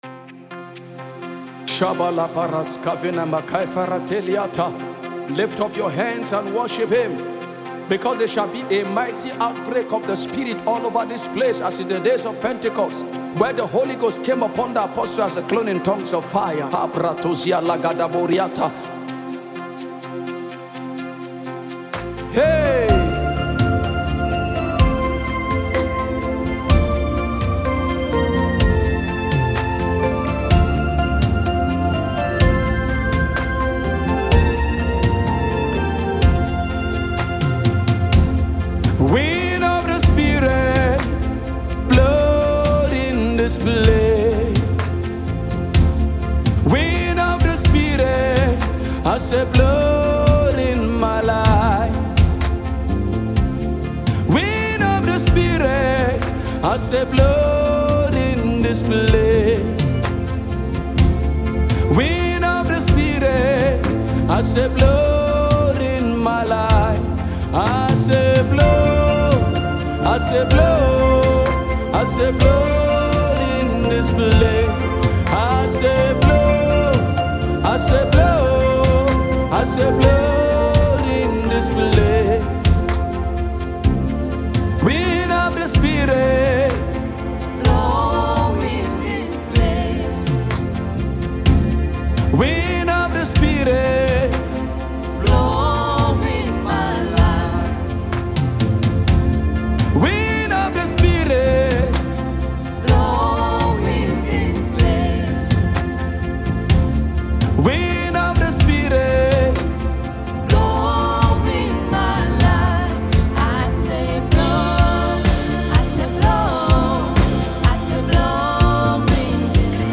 LYRICSNaija Gospel Songs